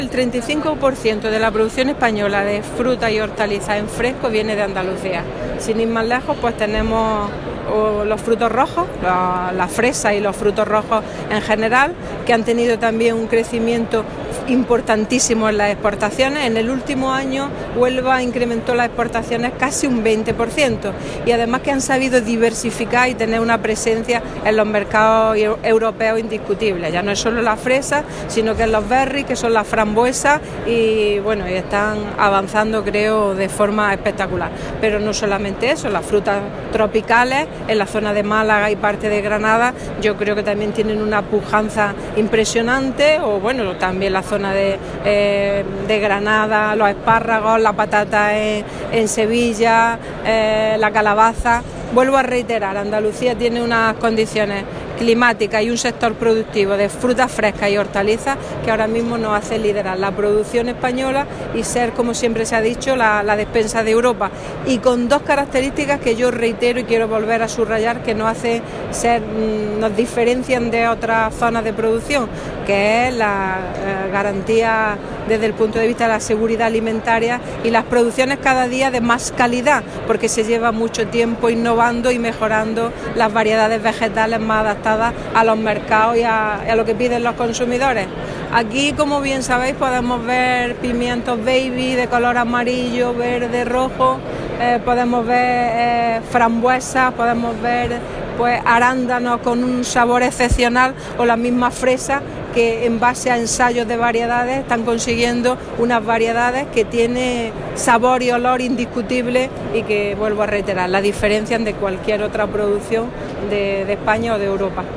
La consejera de Agricultura, Pesca y Desarrollo Rural, Carmen Ortiz, ha visitado la Feria Fruit Logistica en Berlín
Declaraciones de Carmen Ortiz sobre producción hortofrutícola andaluza